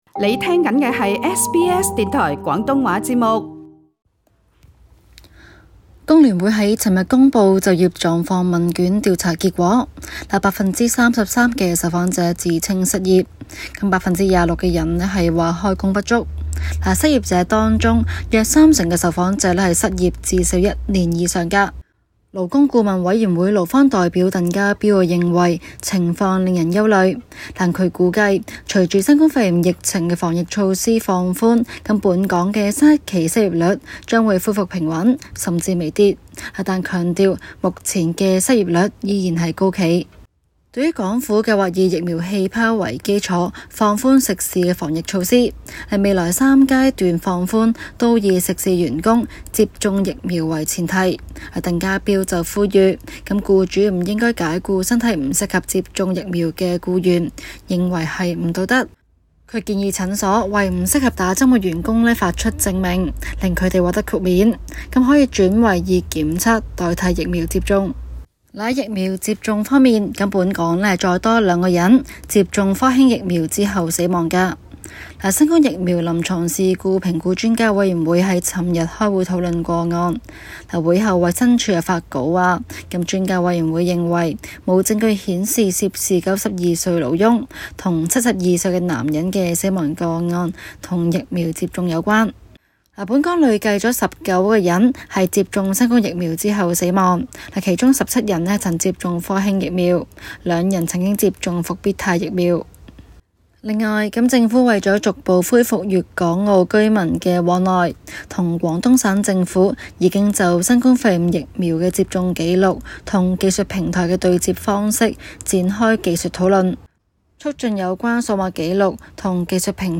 香港新聞綜合報道。